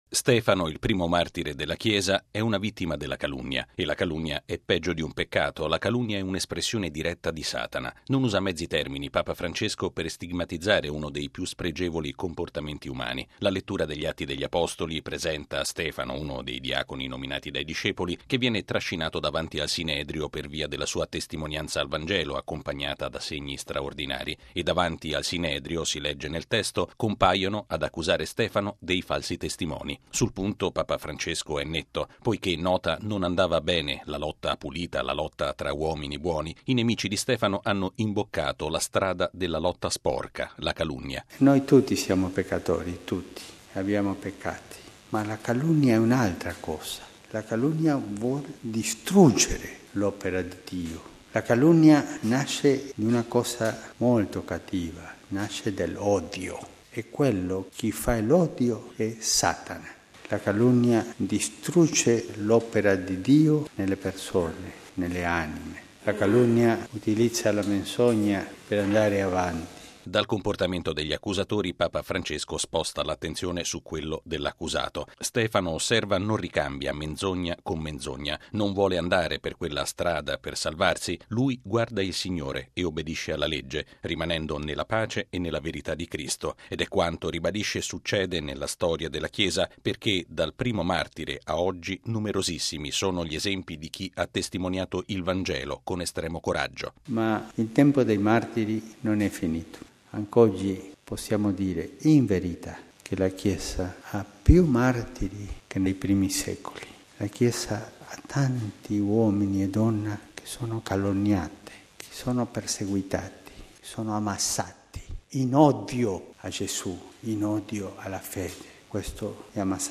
Lo ha affermato questa mattina Papa Francesco all’omelia della Messa presieduta nella cappella della Casa “S. Marta”, alla presenza, fra gli altri, di personale dei Servizi telefonici vaticani e dell’Ufficio Internet vaticano. Il Pontefice ha invitato a pregare per i tanti martiri che anche oggi sono falsamente accusati, perseguitati e uccisi in odio alla fede.